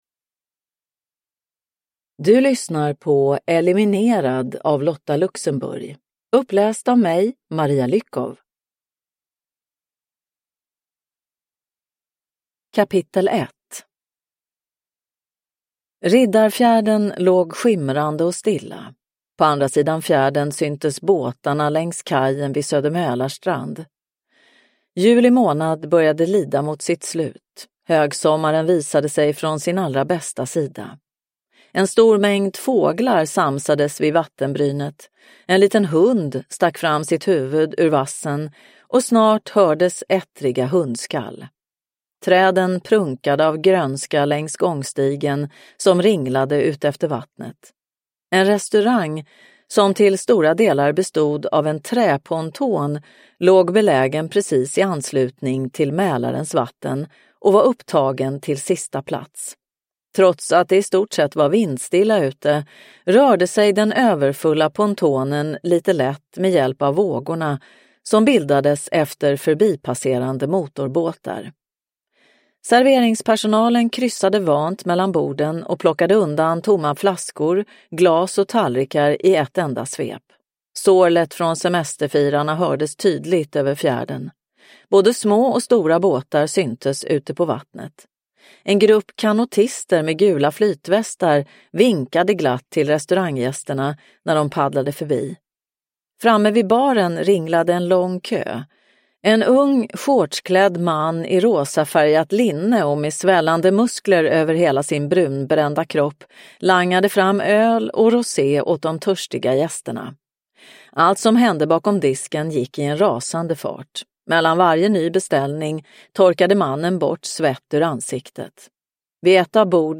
Eliminerad (ljudbok) av Lotta Luxenburg